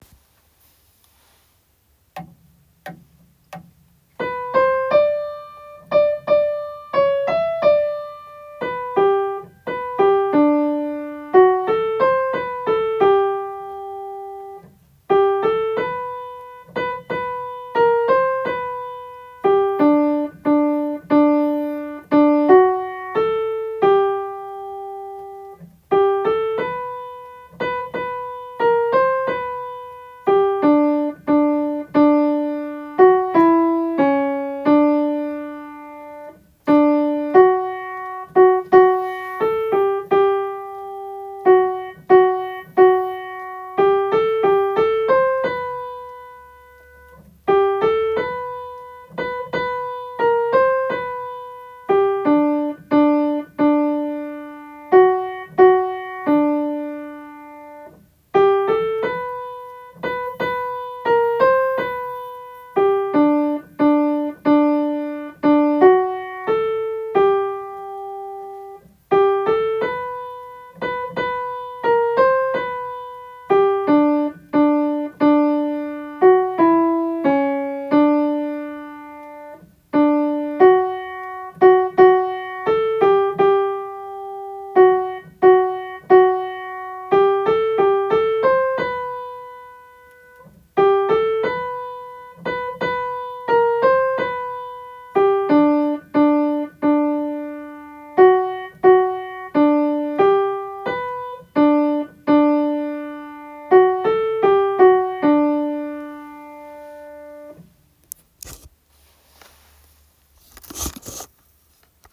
昭和の校歌　メゾソプラノ　2番から　（前奏部、姫の御名こそかぐはしや～）